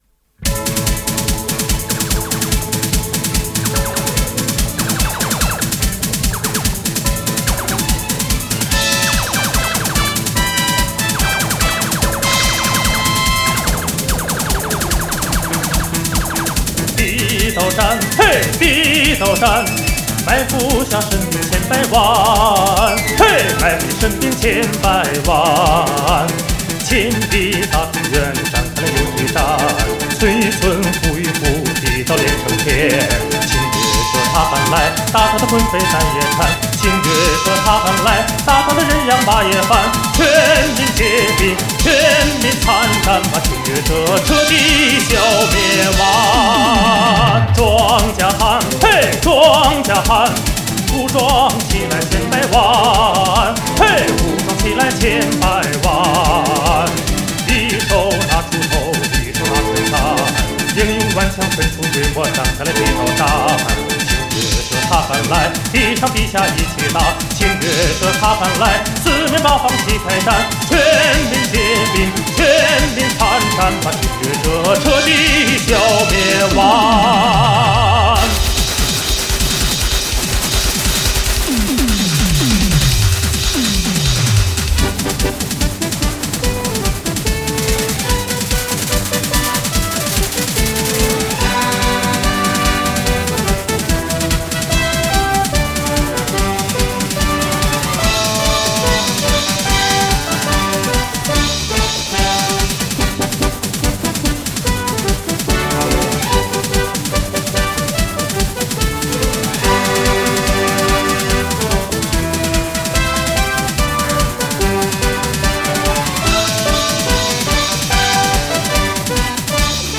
专辑格式：DTS-CD-5.1声道
收录民歌、校园歌曲以及影视歌曲的精彩联奏共302首